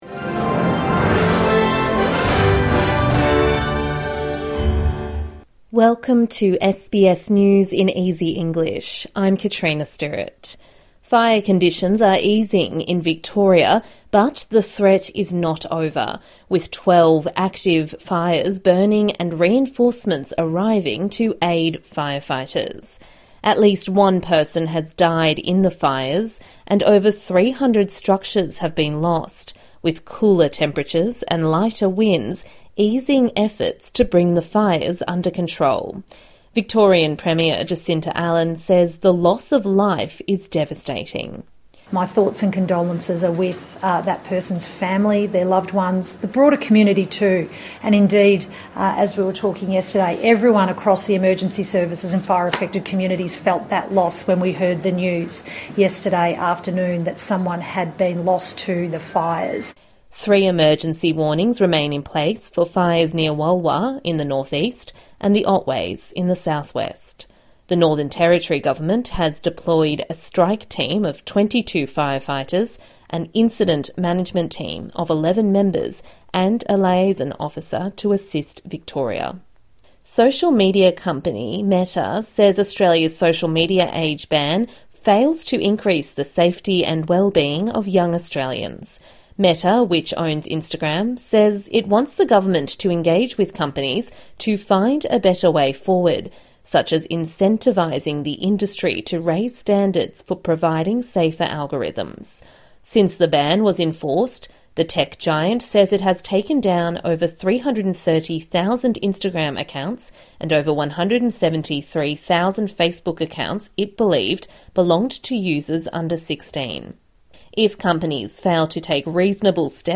A daily 5 minute news bulletin for English learners and people with a disability.